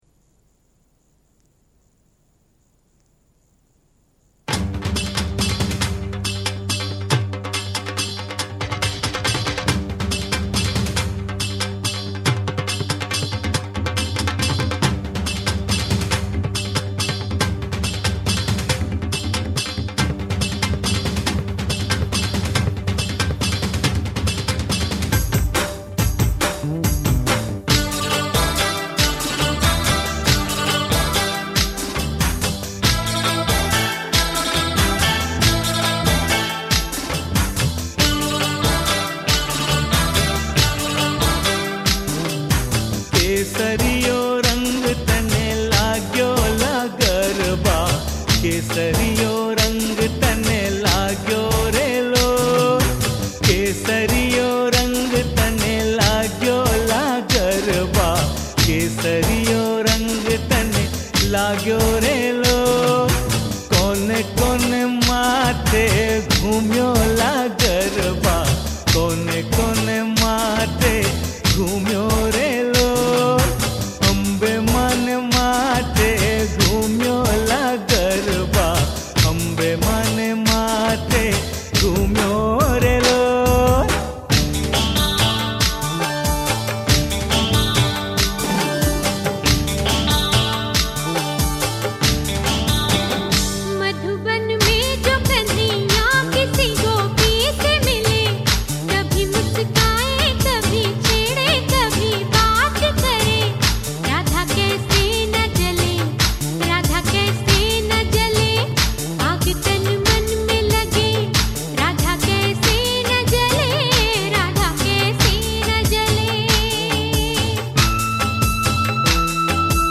Non Stop Dandiya Mix